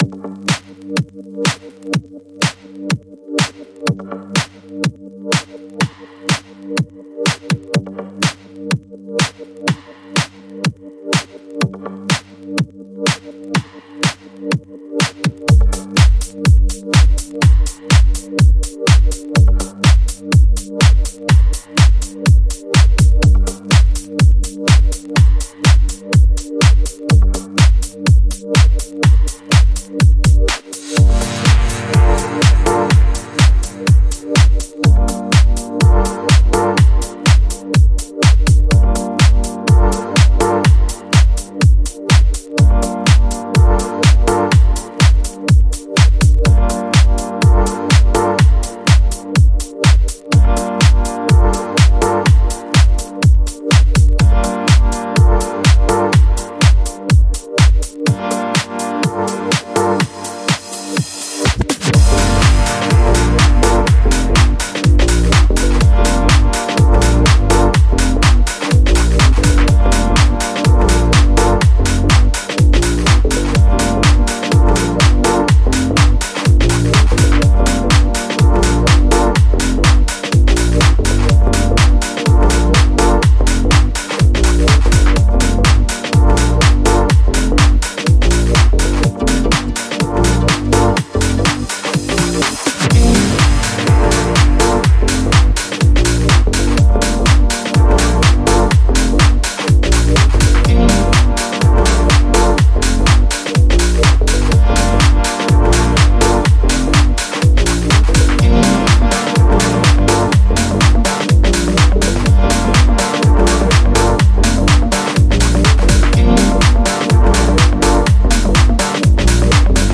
Genre: Minimal / Deep Tech